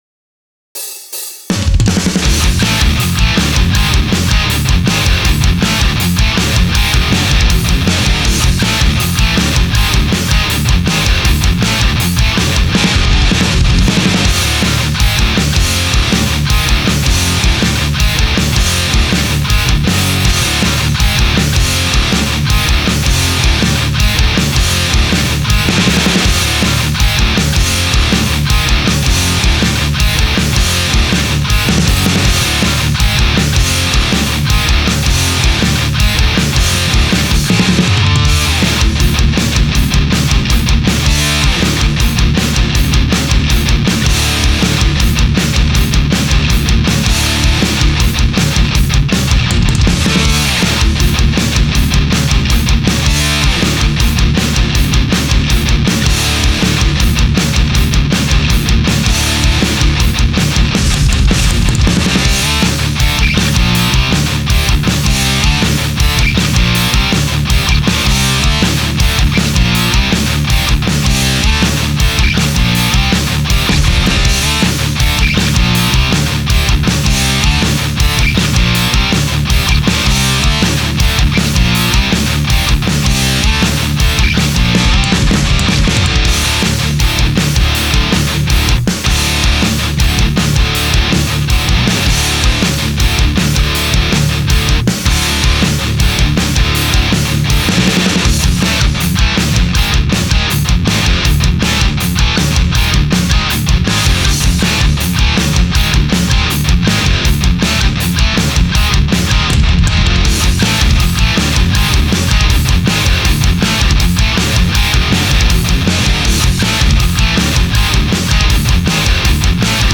ストレートな分かりやすいリフで邪悪を表しました。アグレッシブでキレのあるメタルサウンド。